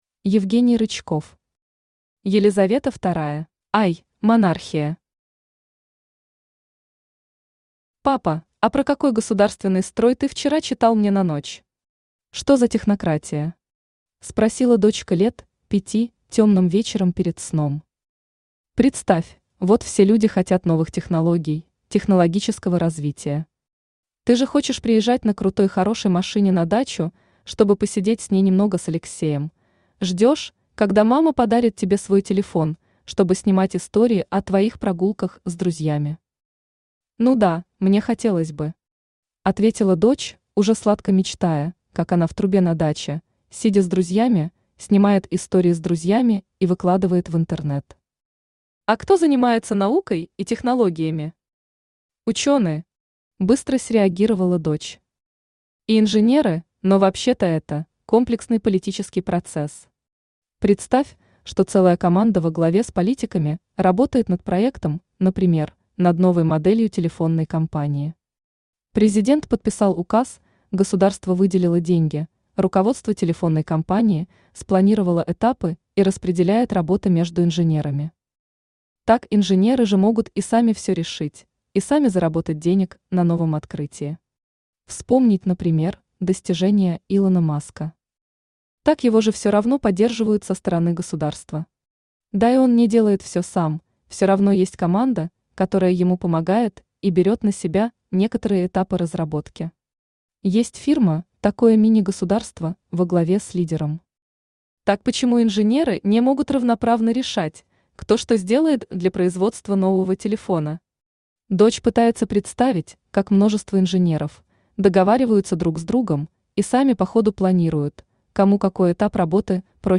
Аудиокнига Елизавета II | Библиотека аудиокниг
Aудиокнига Елизавета II Автор Евгений Николаевич Рычков Читает аудиокнигу Авточтец ЛитРес.